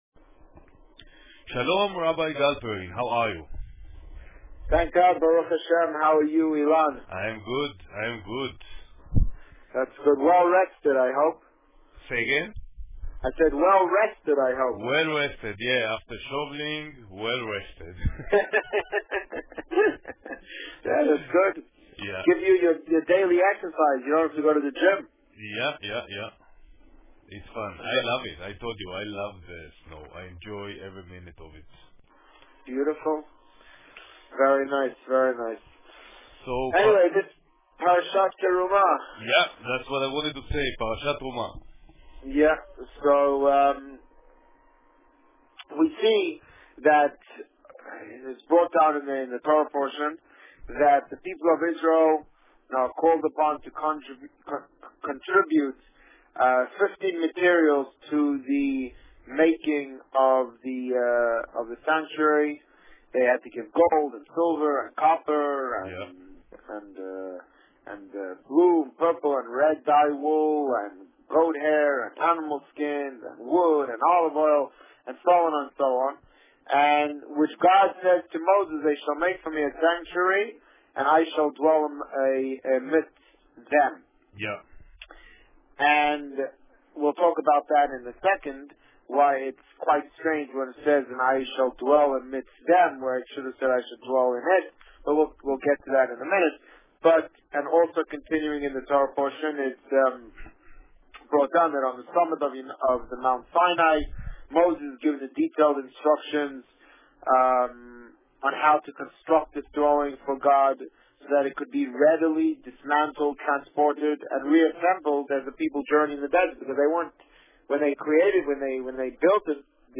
You are here: Visitor Favourites The Rabbi on Radio The Rabbi on Radio Parsha Terumah Published: 03 February 2011 | Written by Administrator On February 3, 2011, the Rabbi spoke about Parsha Terumah.